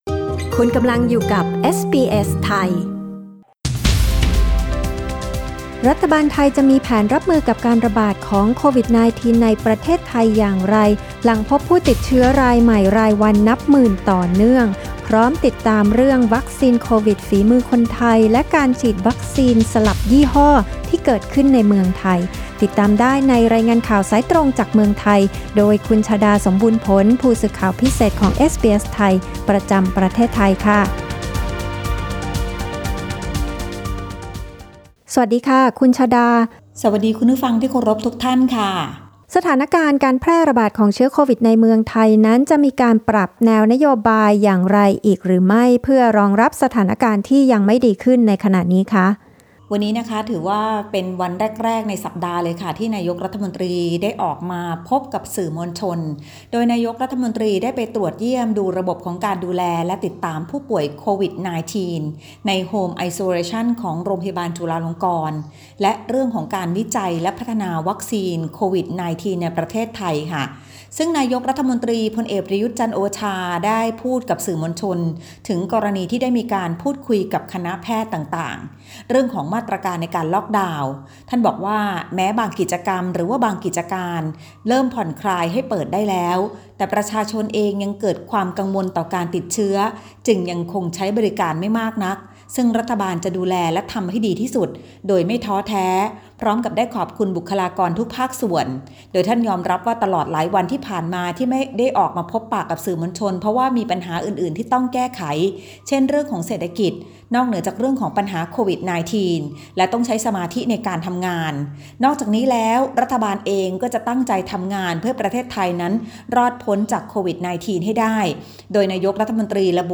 ฟังรายงานข่าว